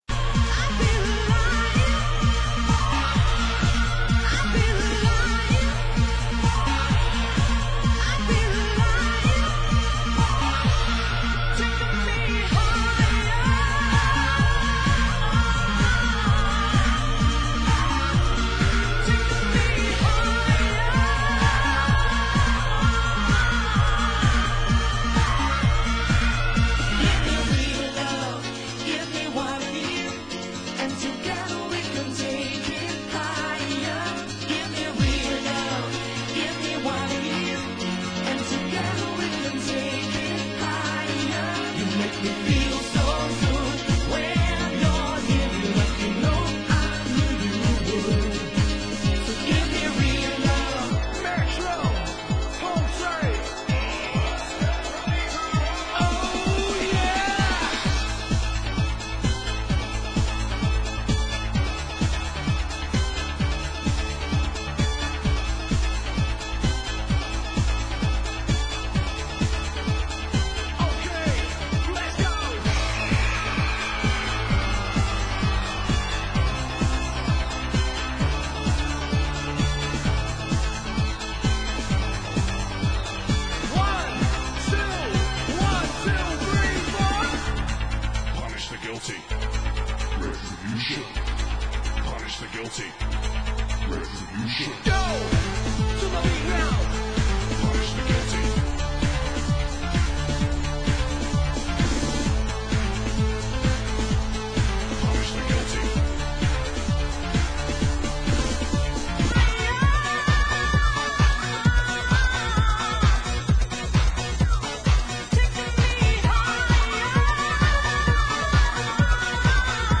Genre: Synth Pop